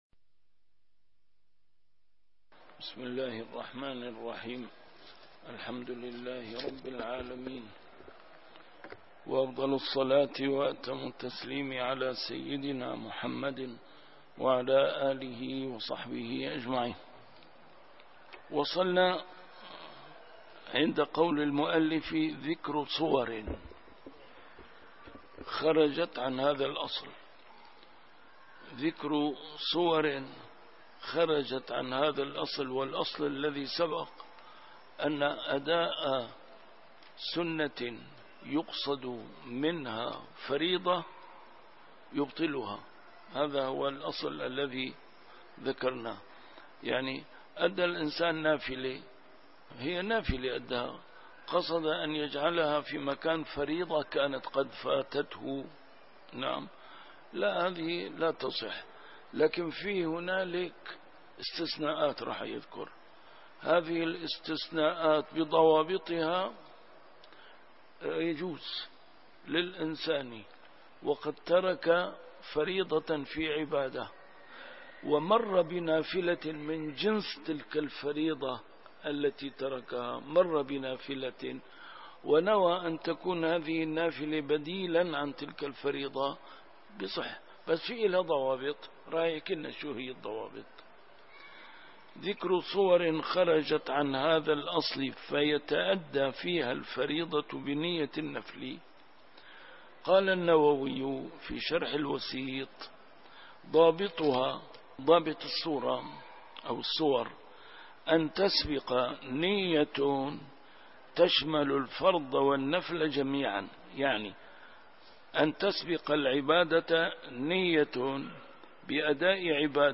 A MARTYR SCHOLAR: IMAM MUHAMMAD SAEED RAMADAN AL-BOUTI - الدروس العلمية - كتاب الأشباه والنظائر للإمام السيوطي - كتاب الأشباه والنظائر، الدرس السادس والعشرون: ما يتأدى فيه الفرض بنية النفل.